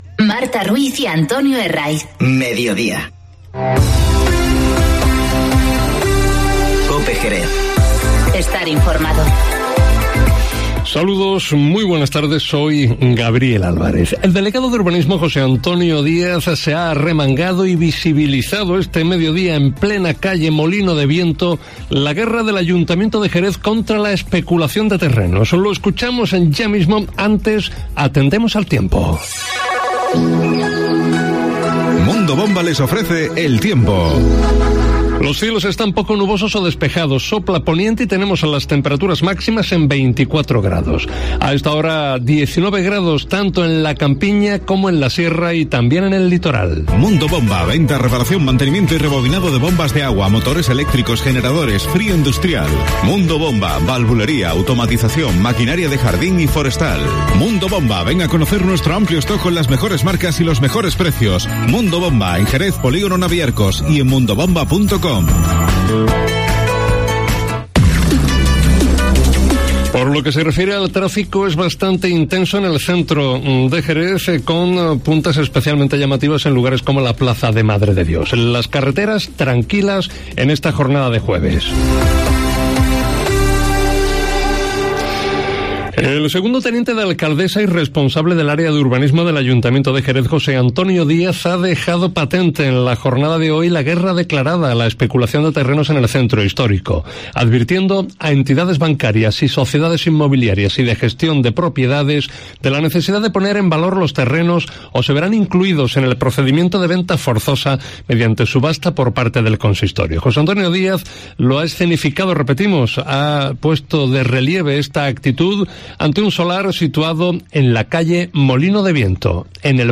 Informativo Mediodía COPE en Jerez 18-10-19